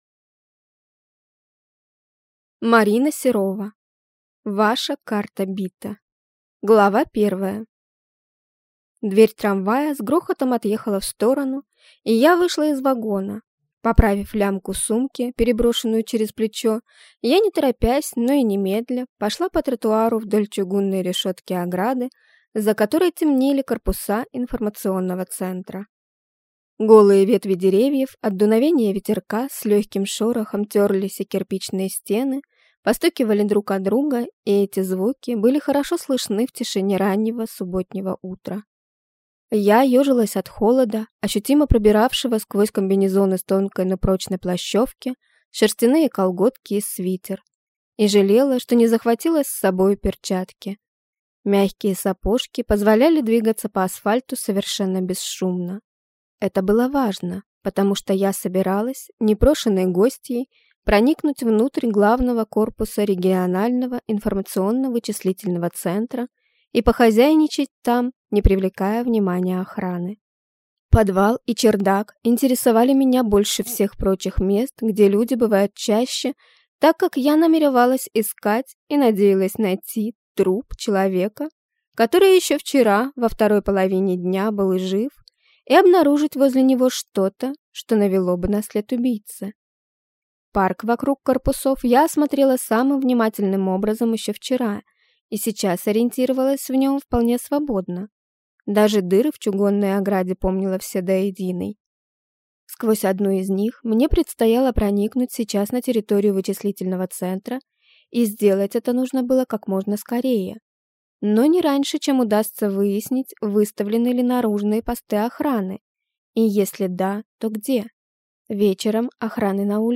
Аудиокнига Ваша карта бита | Библиотека аудиокниг
Прослушать и бесплатно скачать фрагмент аудиокниги